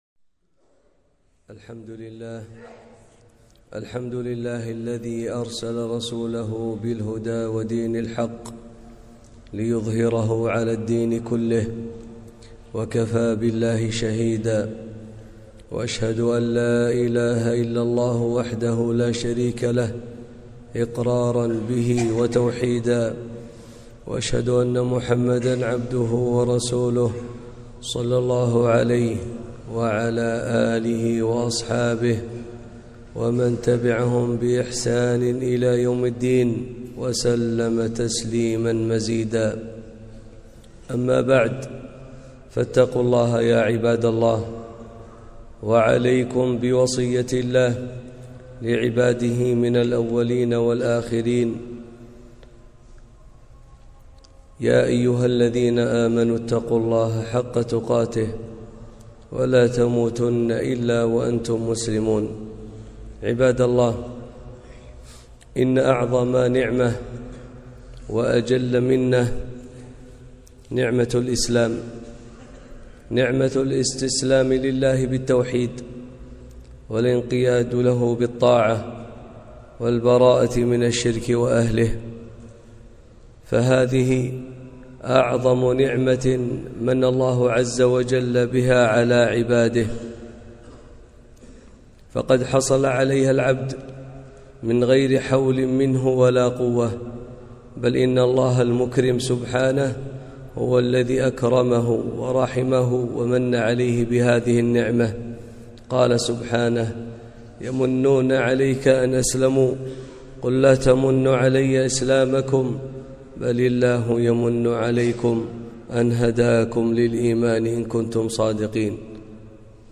خطبة - الإسلام أعظم النعم